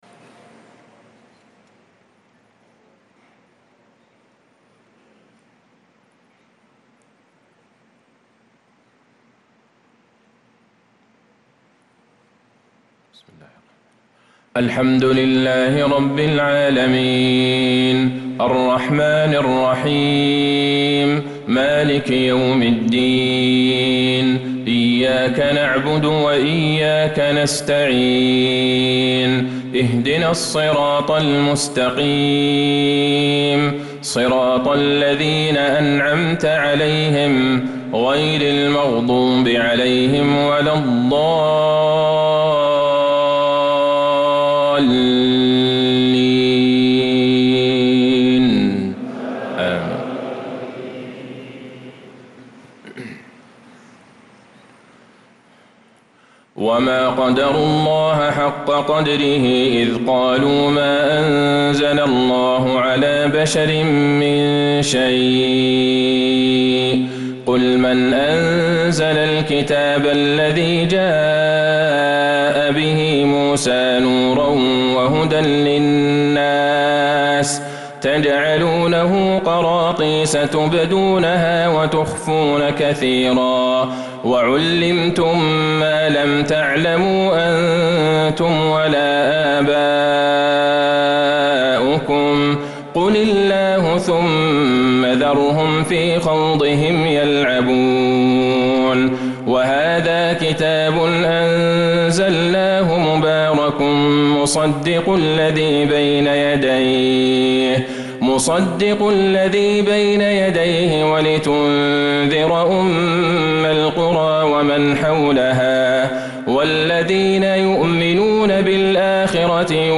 عشاء الجمعة 3-3-1446هـ من سورة الأنعام | Isha prayer from Surah Al-An’aam 6-9-2024 > 1446 🕌 > الفروض - تلاوات الحرمين